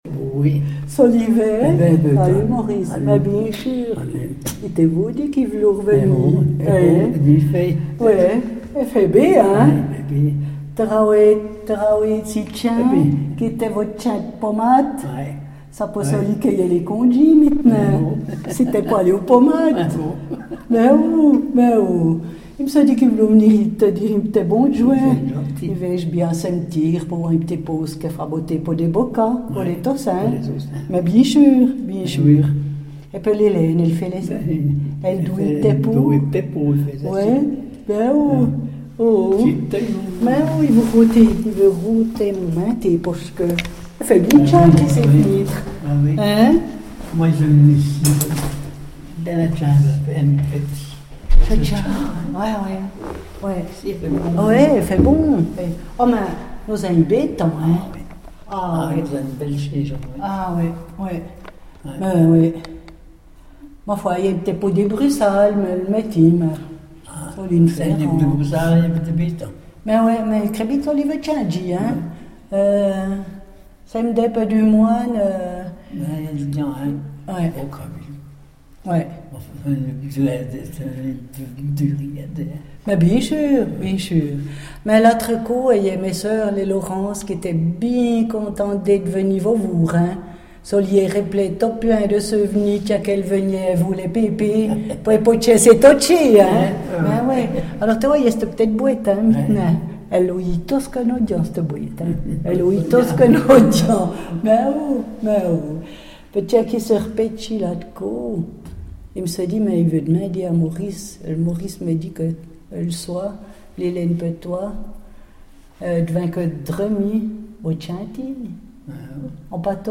parlent le patois du Val Terbi.